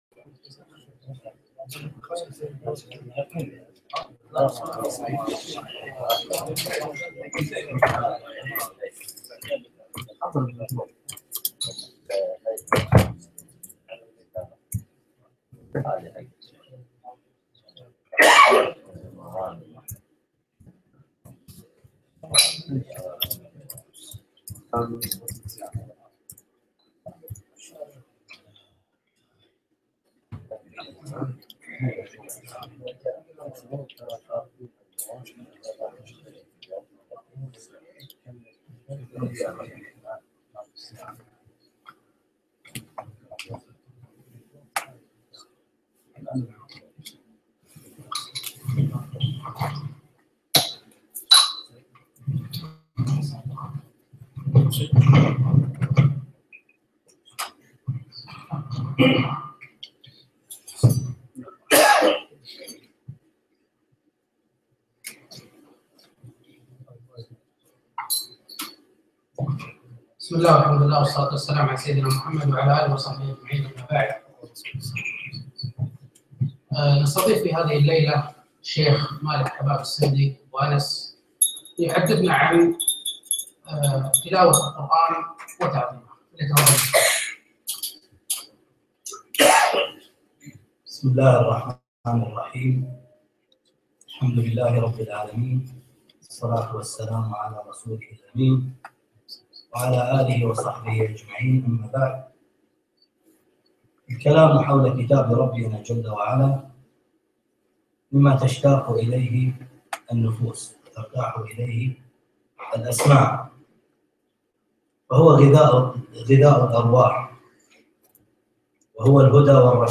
محاضرة - تلاوة القرآن وتعظيمه